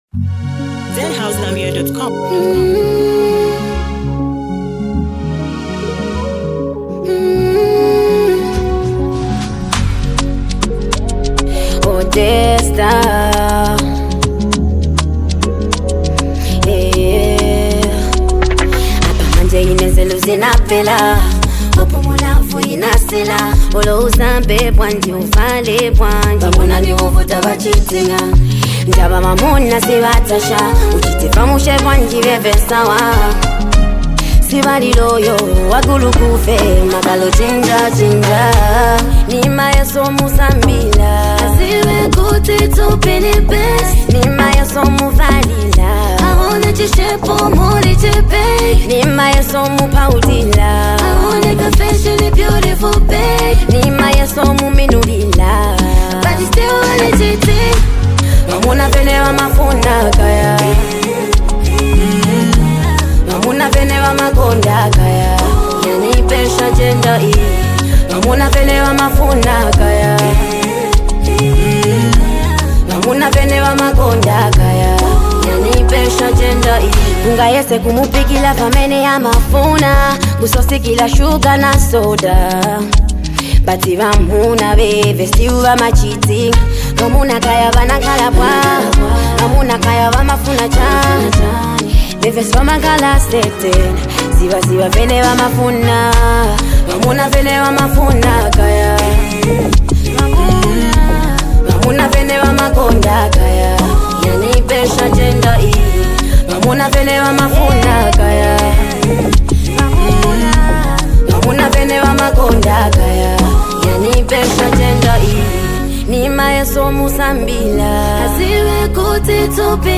heartfelt emotion